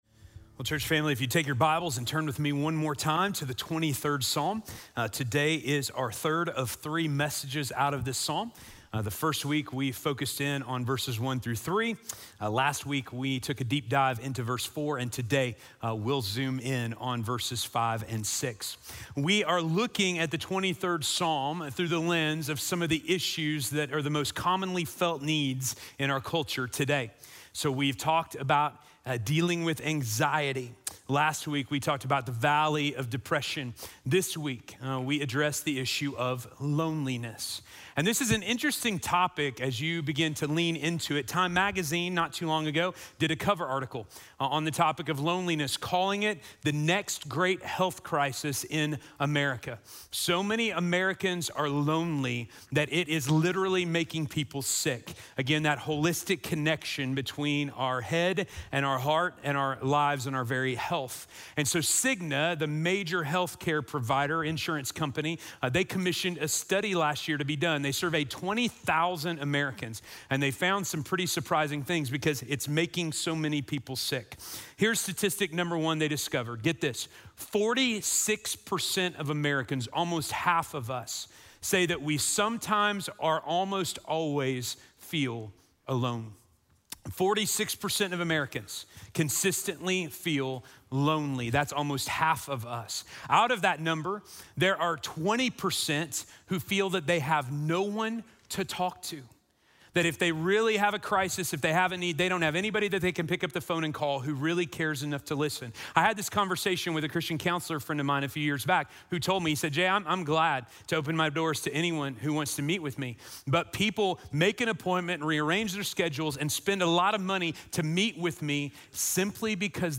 Dealing with Loneliness - Sermon - Station Hill